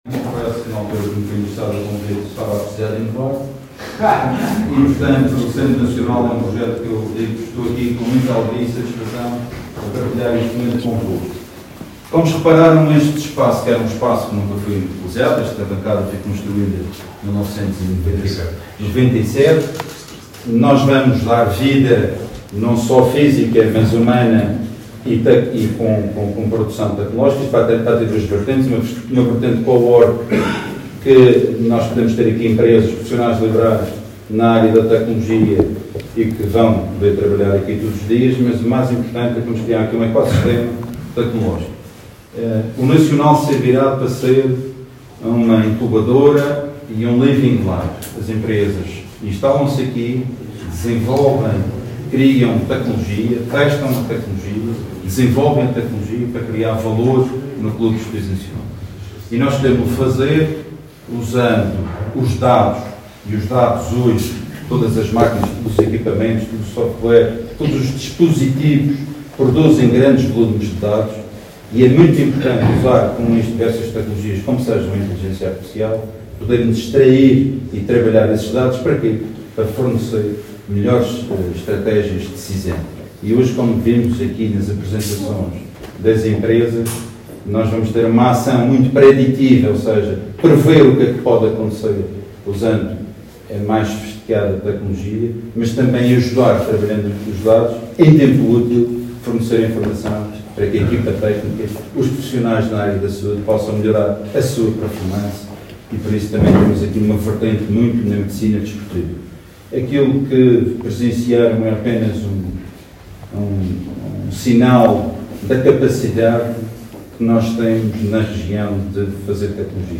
ÁUDIOS | discursos: